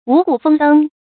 五谷豐登 注音： ㄨˇ ㄍㄨˇ ㄈㄥ ㄉㄥ 讀音讀法： 意思解釋： 五谷：指稻、黍（小米）、稷（高梁）、麥、菽（豆）泛指糧食作物；豐登：豐收上場打曬。